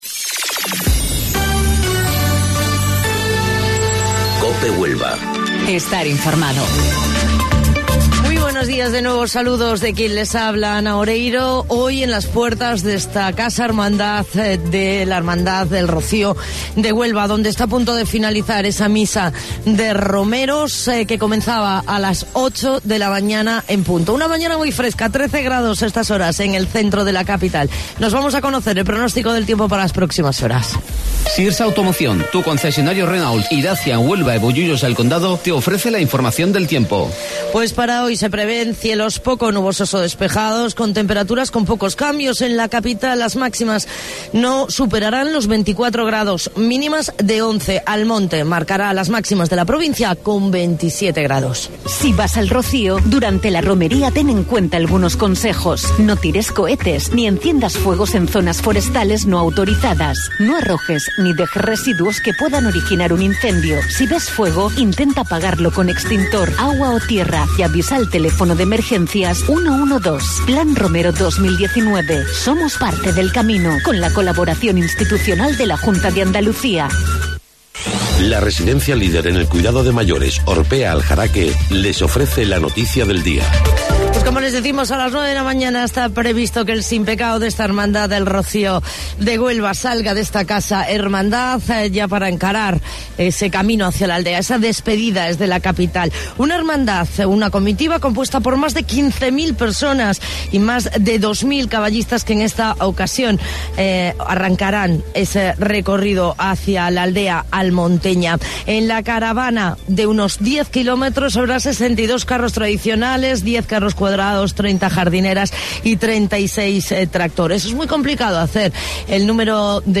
AUDIO: Informativo Local 08:25 del 6 de Junio